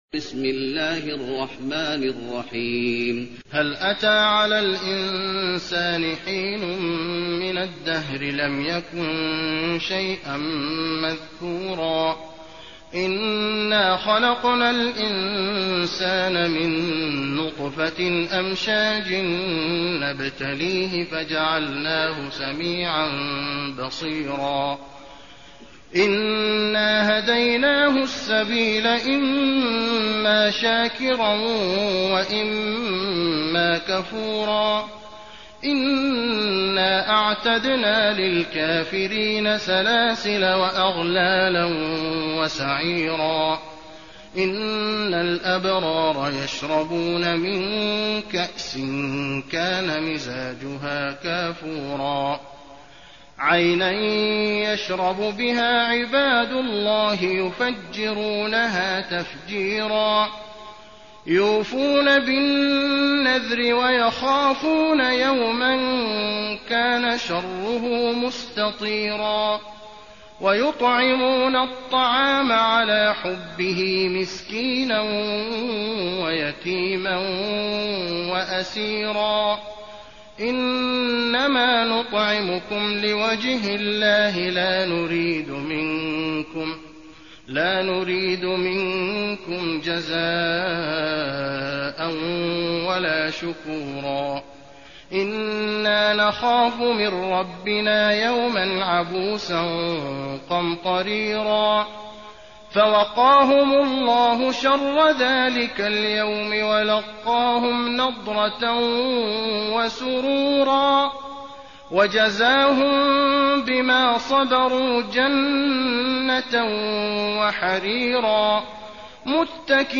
المكان: المسجد النبوي الإنسان